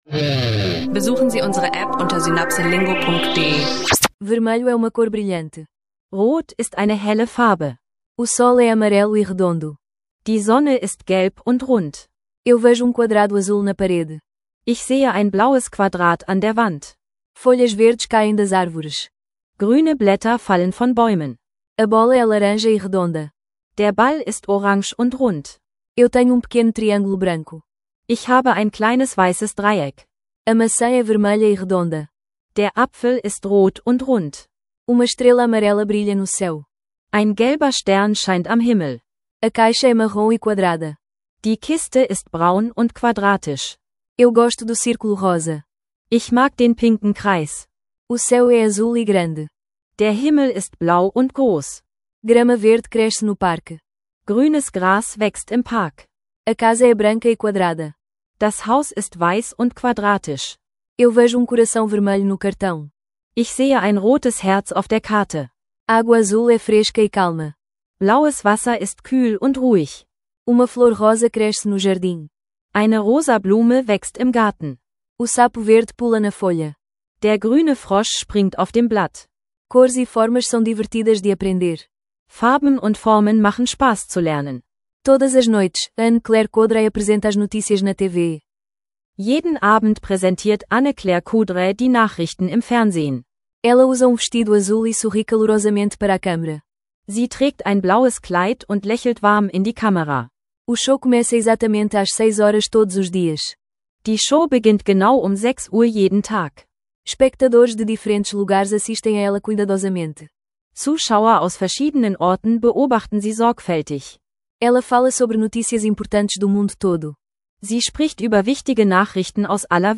Hören und sprechen Sie Portugiesisch mit unseren praktischen Audiolektionen!